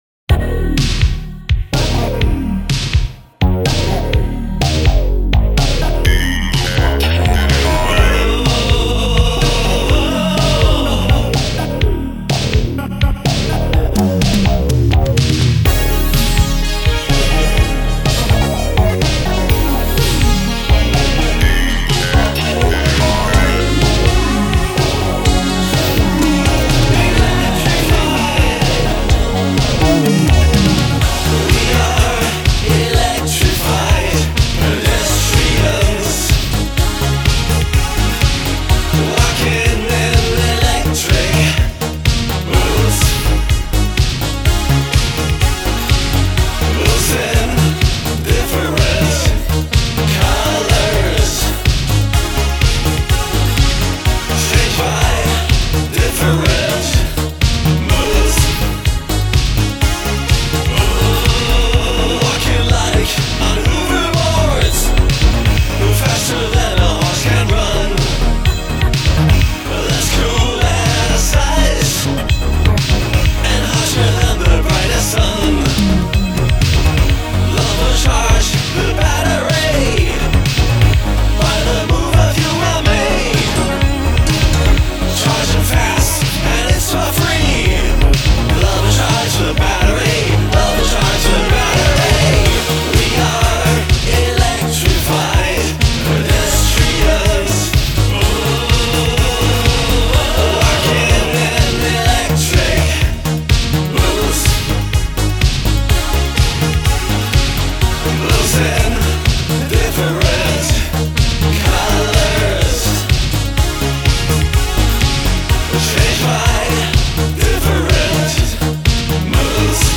Italo/Euro-Disco / Laserdance Contest 2024: !!!
Hier mal meine Stimme in einem Italo-Disco Stück: